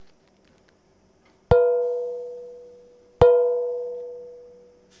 マイクロフォンは、SONYのワンポイントステレオ録音用、ECM-MS907を使いました。
台所のフライパンを、マリンバ用のマレットで軽く打ってみました。
マレットで打ちましたから、”わ〜〜〜〜ん”てな音です。
fling-pan1.wav